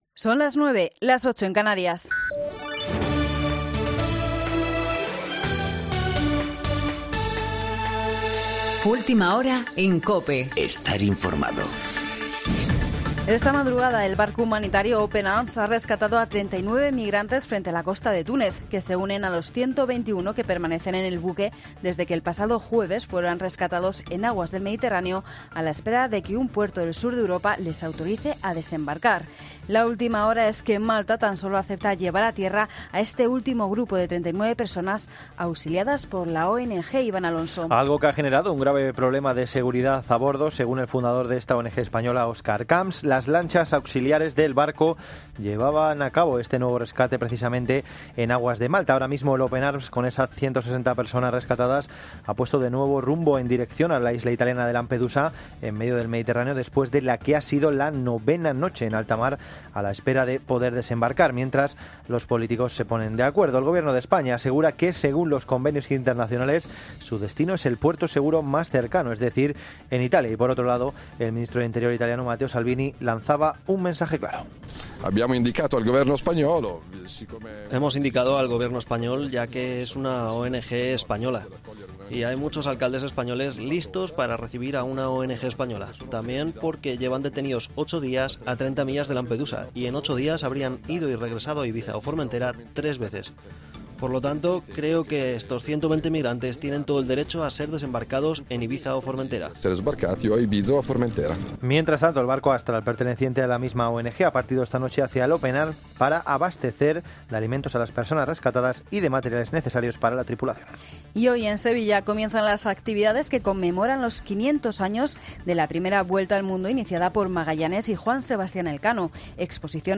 Boletín de noticias COPE del 10 de agosto de 2019 a las 09.00 horas